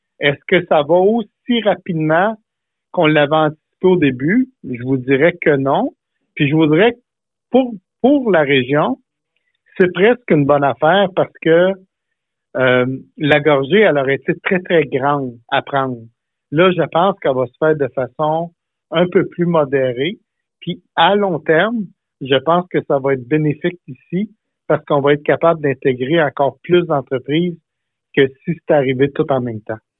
En entrevue, Donald Martel a reconnu que le développement ne se fait pas au rythme initialement prévu pour la filière batterie.